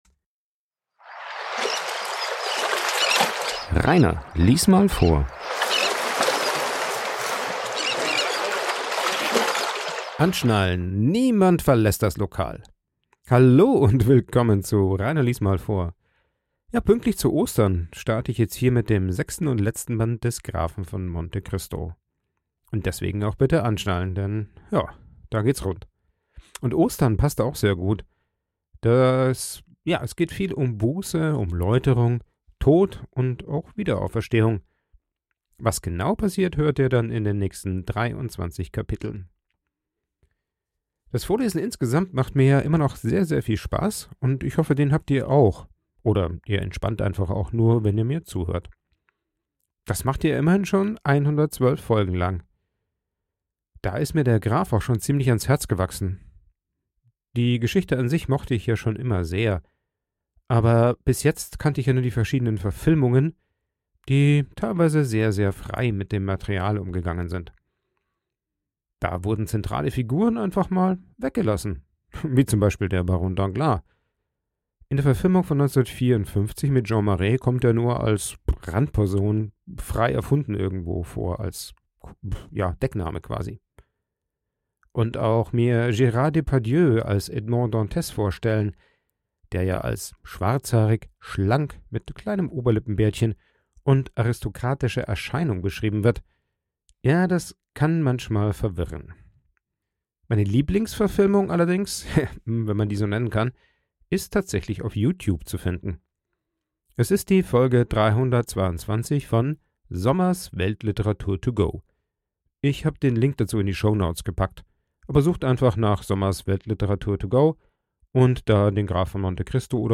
Der Vorlese Podcast
Ein Vorlese Podcast